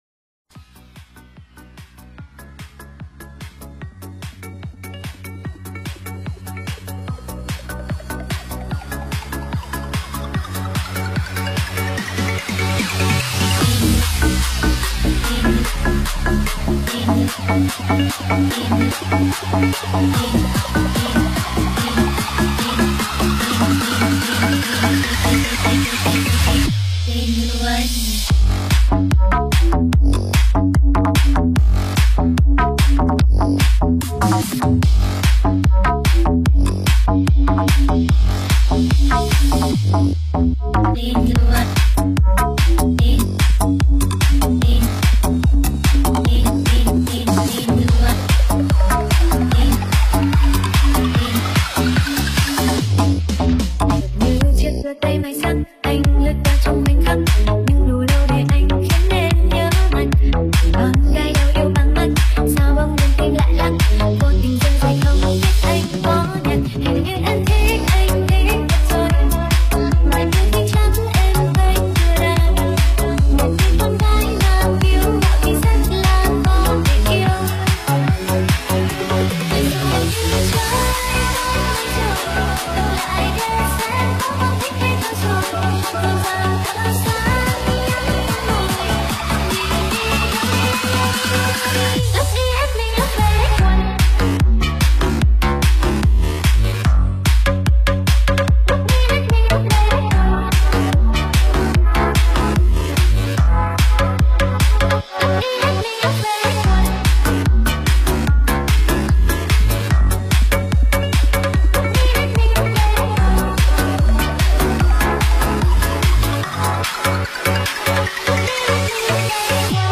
Nonstop Việt Mix